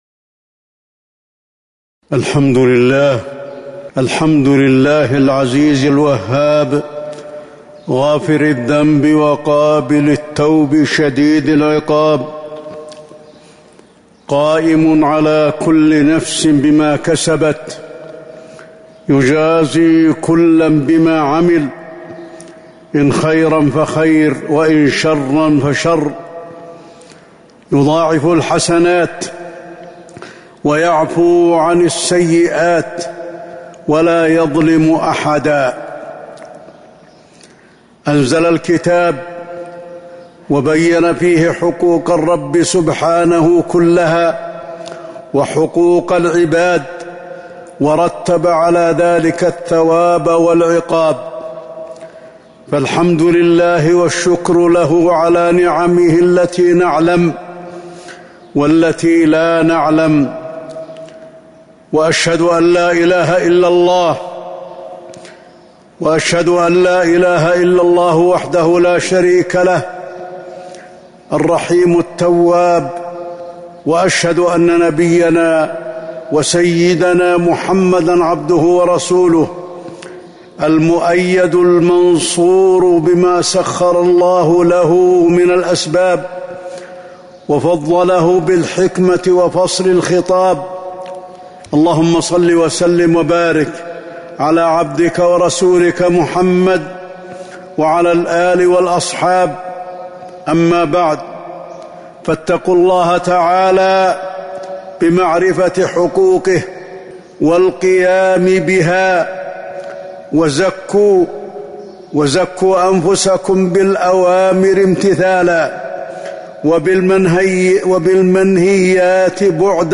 تاريخ النشر ٢٩ شعبان ١٤٤٣ هـ المكان: المسجد النبوي الشيخ: فضيلة الشيخ د. علي بن عبدالرحمن الحذيفي فضيلة الشيخ د. علي بن عبدالرحمن الحذيفي تحقيق العبودية